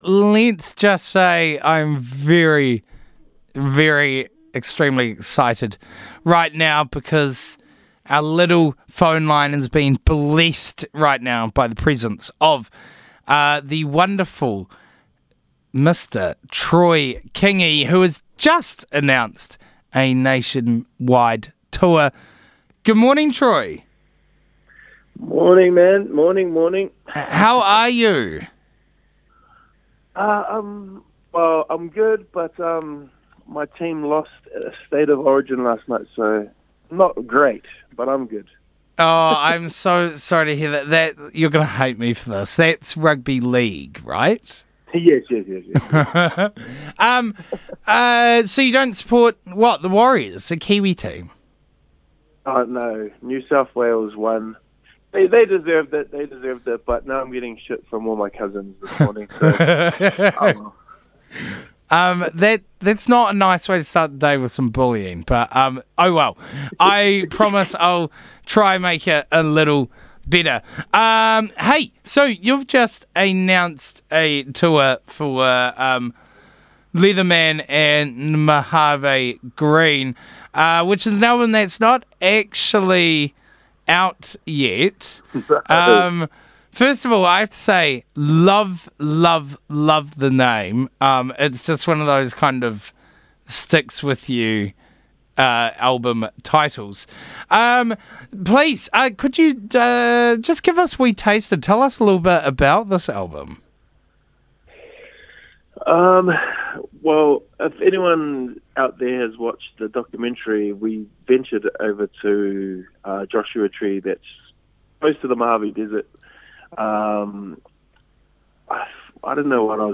Taking place roughly a month after the album’s release, Troy dialled into the Burnt Breakfast to give the lowdown on what’s happening.